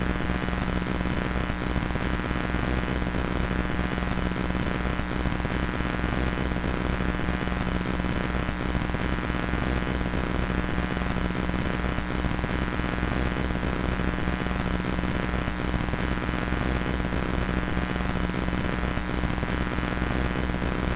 電気音
電流ノイズ
current_noise.mp3